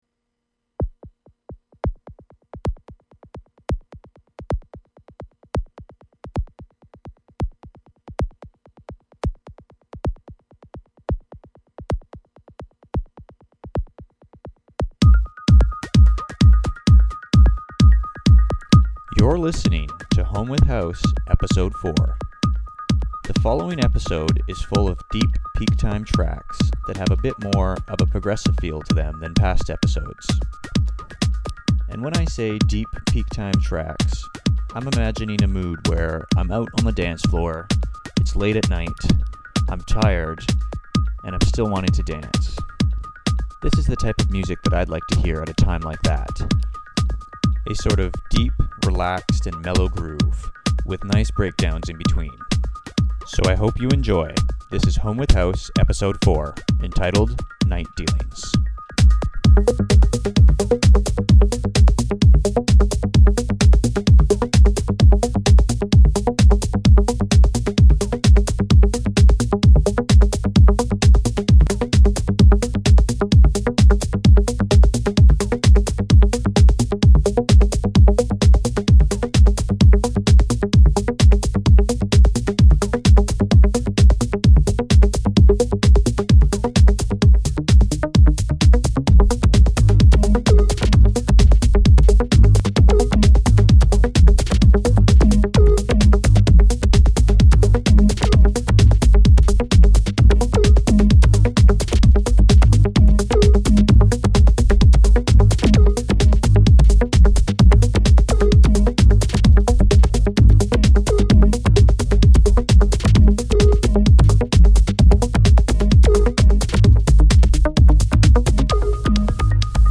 Fabulous mix, as always.
Vibe: Deep House , Groove House